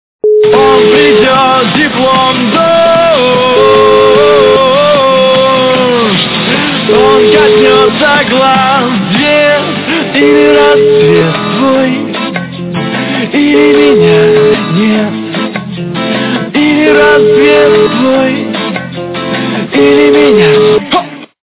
русская эстрада
качество понижено и присутствуют гудки.